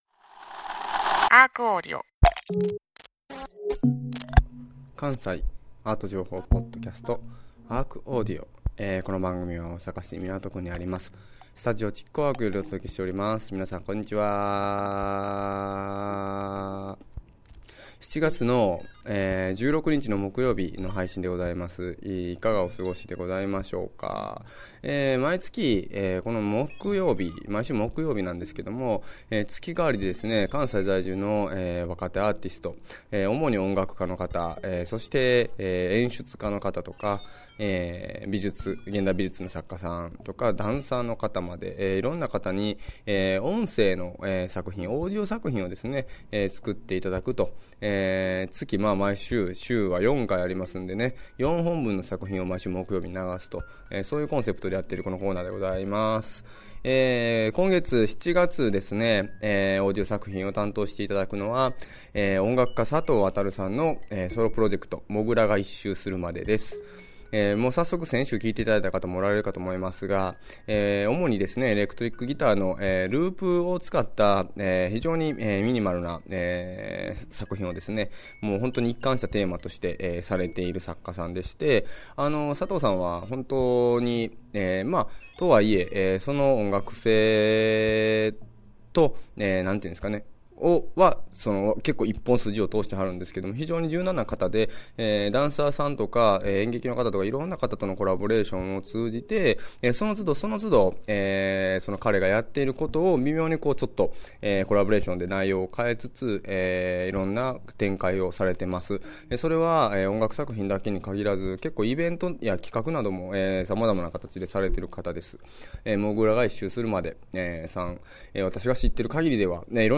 それをまあ今回はもっともっとシンプルな感じに。
エレクトリックギターを使用してのミニマルミュージックをテーマにあらゆる場所で活動。